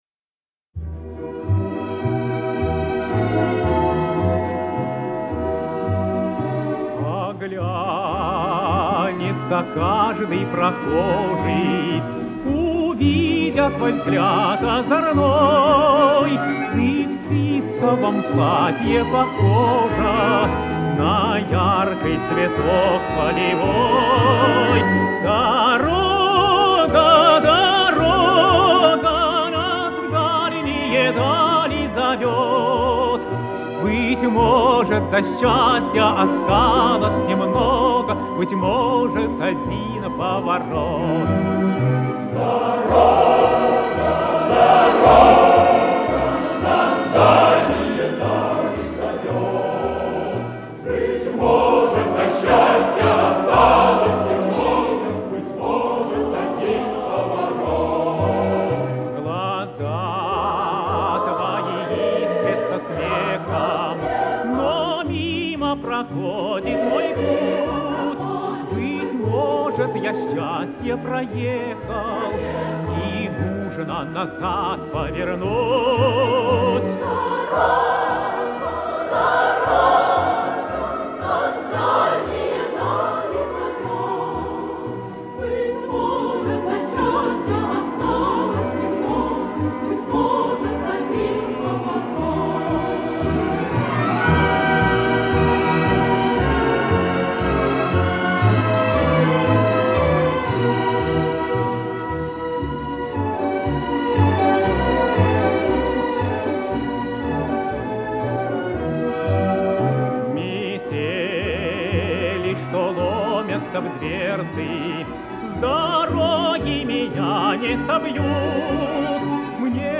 Одна из лучших советских лирических попутных песен